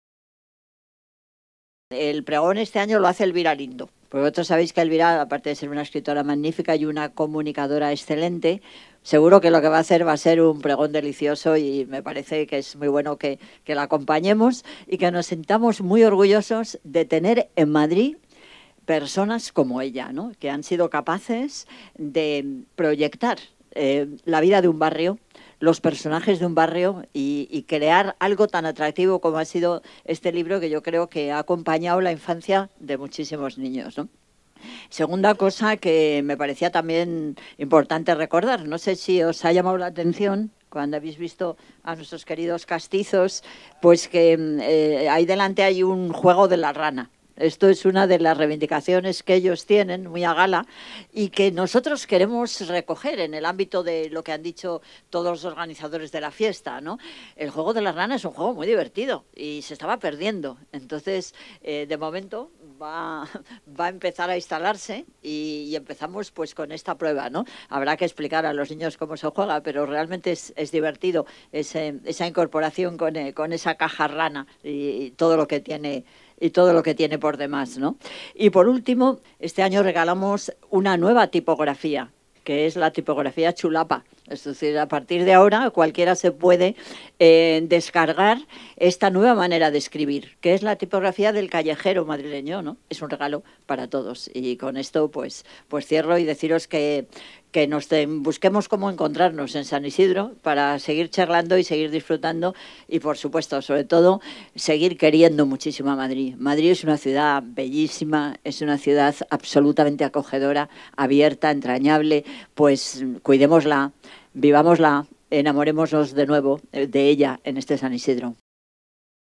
La alcaldesa de Madrid, Manuela Carmena, ha elogiado el trabajo de Elvira Lindo: